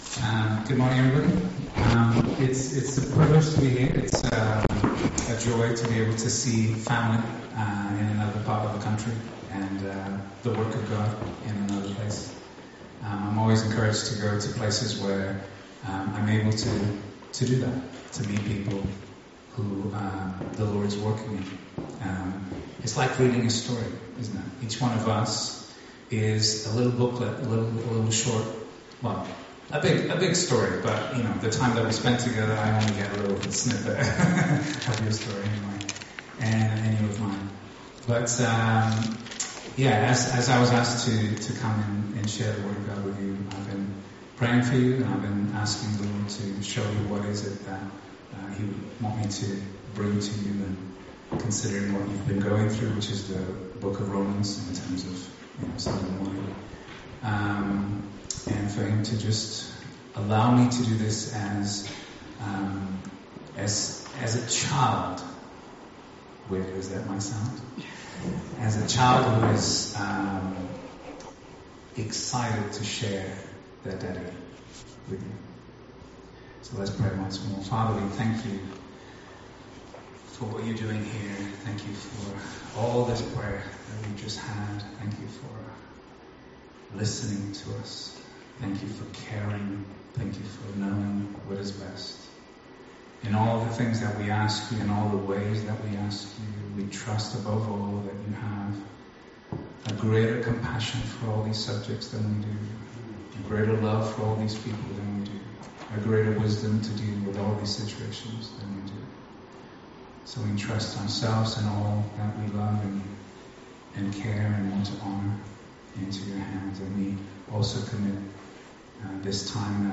Series: Guest Speakers , Sunday morning studies Tagged with guest speakers , The Eternal Gospel , topical studies